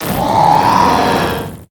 Cri de Dratatin dans Pokémon HOME.